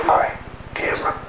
All of the investigations were conducted between 12 midnight and 3am over a two day period.
EVP's